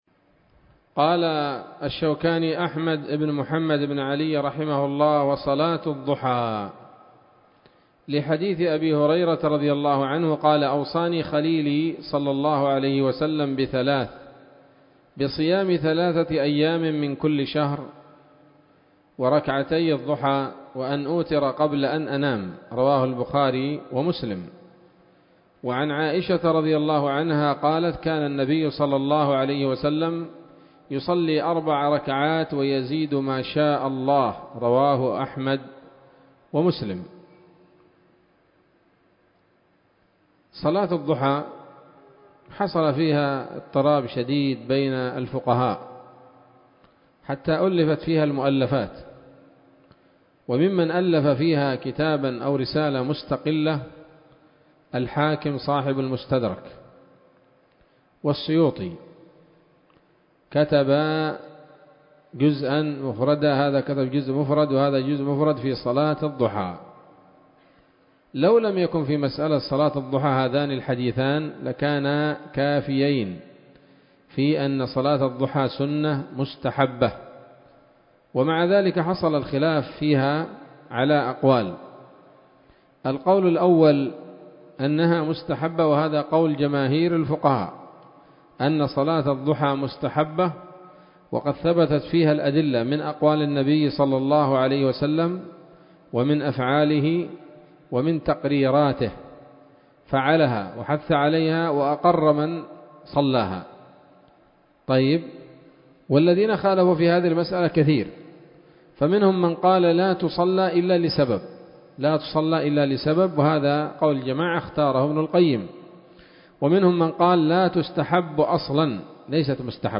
الدرس الحادي والعشرون من كتاب الصلاة من السموط الذهبية الحاوية للدرر البهية